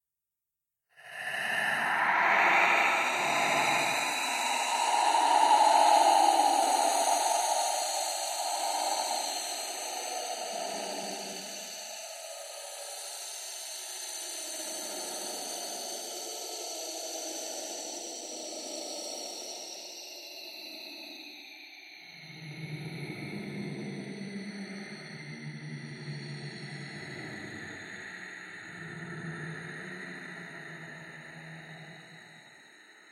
咆哮的僵尸
描述：喝牛奶以获得大量的痰，并在mixcraft 6中记录有效的咆哮。
标签： 怪物 邪恶 可怕的 邪恶的 撒旦 怪异 恶魔 僵尸 恶魔 咆哮 妖附身 黑暗 视频游戏
声道立体声